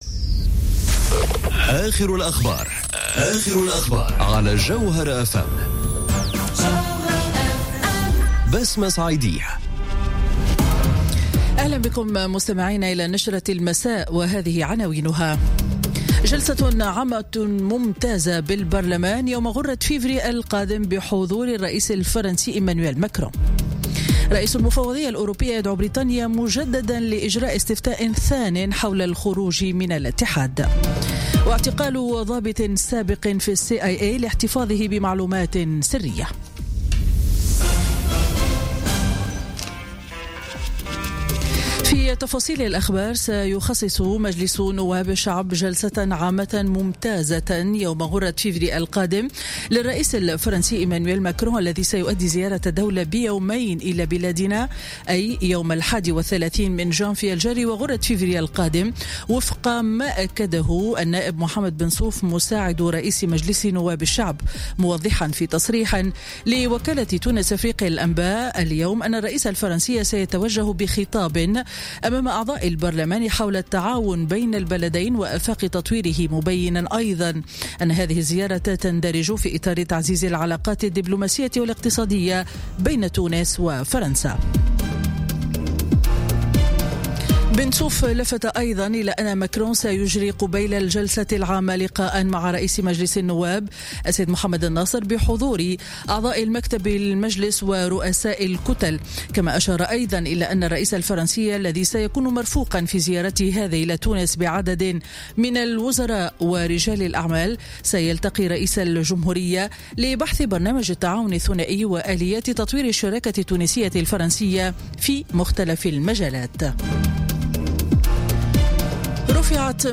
نشرة أخبار السابعة مساءً ليوم الأربعاء 17 جانفي 2018